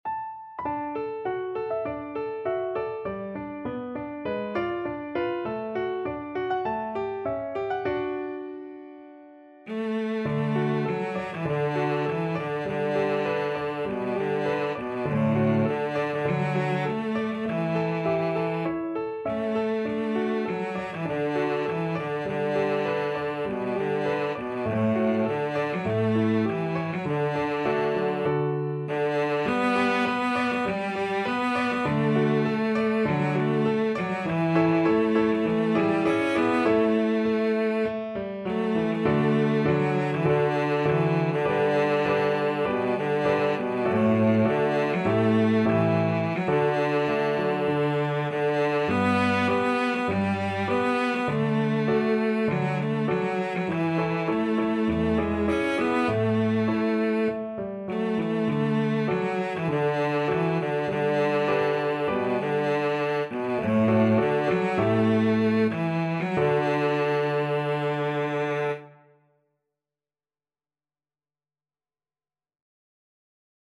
Cello
D major (Sounding Pitch) (View more D major Music for Cello )
~ = 100 Moderato
4/4 (View more 4/4 Music)
A3-Db5
Traditional (View more Traditional Cello Music)